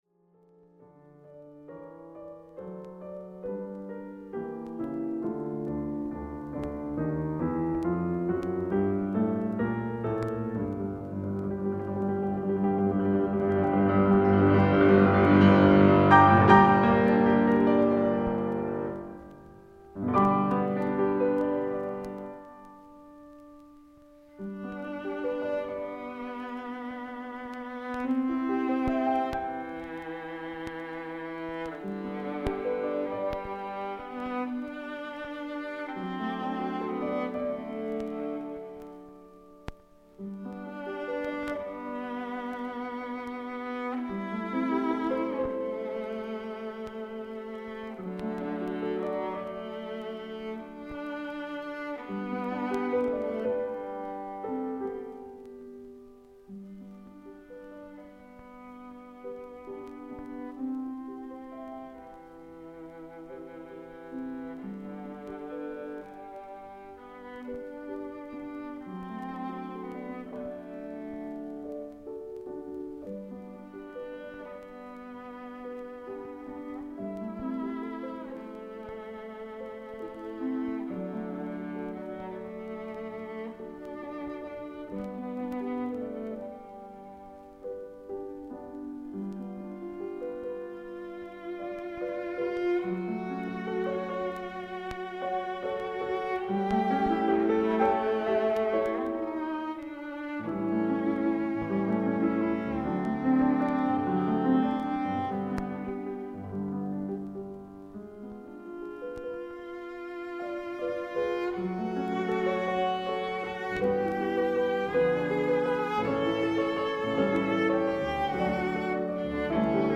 美しいヴィオラの音です（カントゥーシャ作のヴィオラも共通する音色があります）。